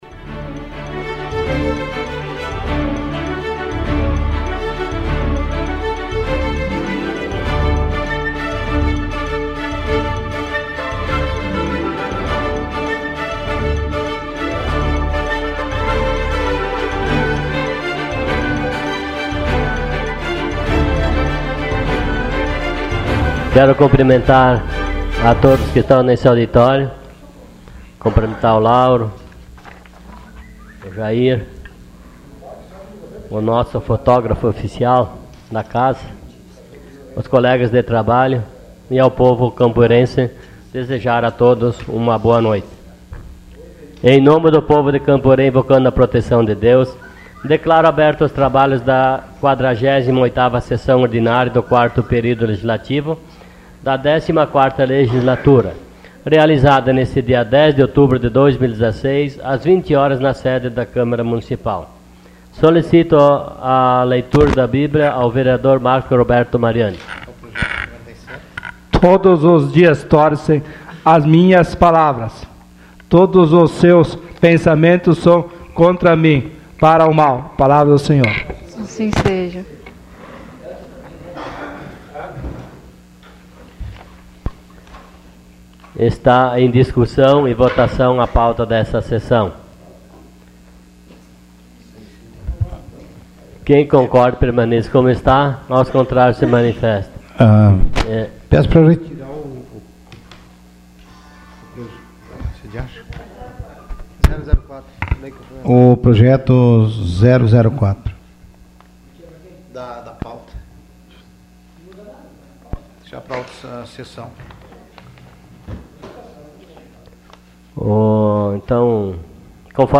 Sessão Ordinária dia 10 de outubro de 2016.